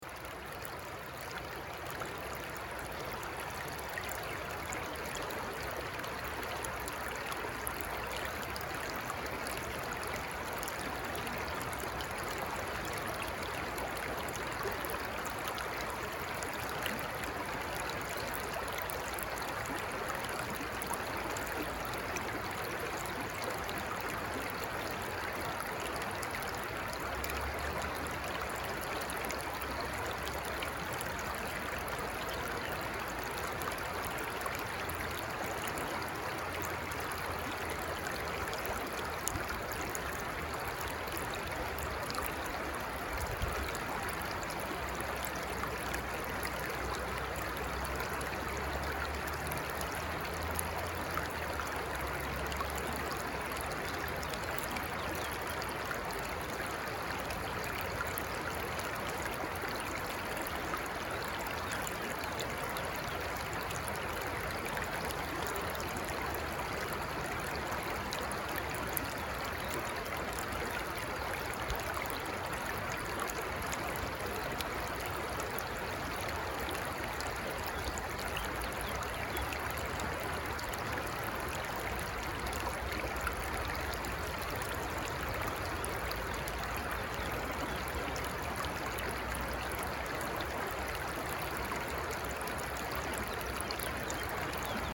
Звуки журчания ручья скачать и слушать онлайн
shum-ruchia-v-reku.mp3